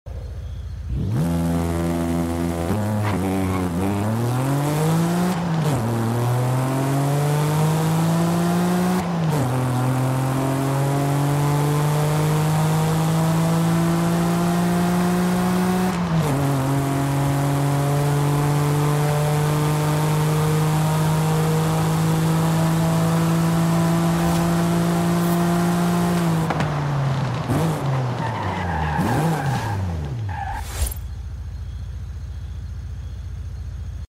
2008 BMW Z4 M Coupe sound effects free download
2008 BMW Z4 M Coupe Launch Control & Sound - Forza Horizon 5